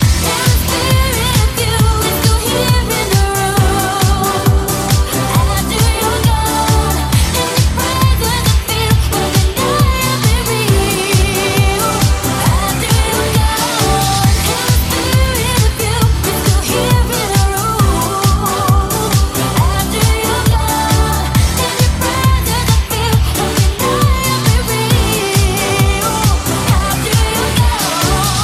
Genere: dance, house, electro, remix, 2009